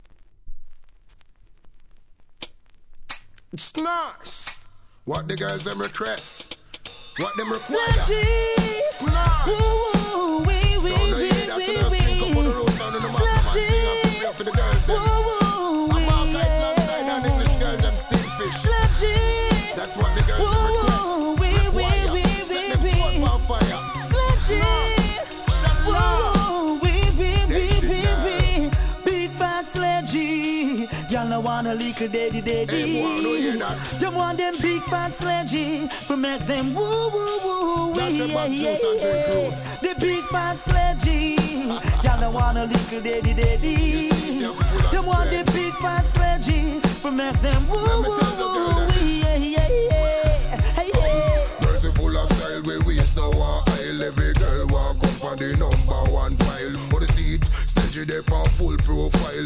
REGGAE
HIP HOP調のRHYTHMで好コンビネーション!!